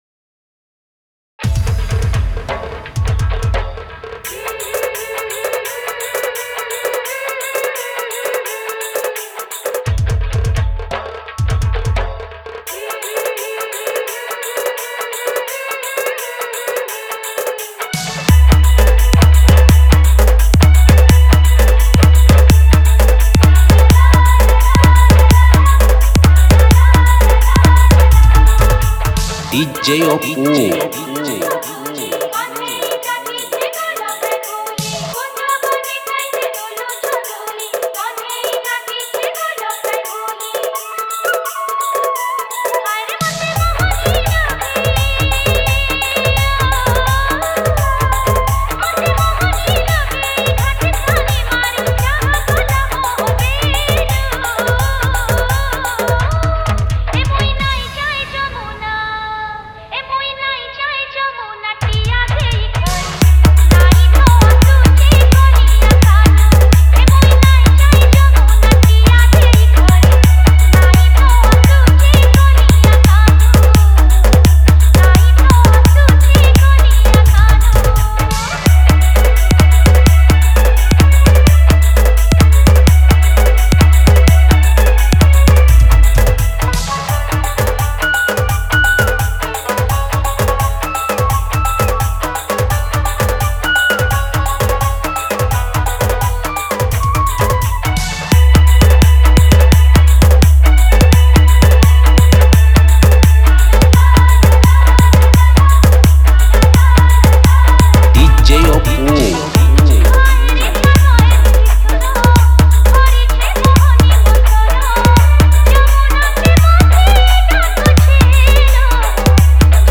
Odia Bhakti Tapori Dance Mix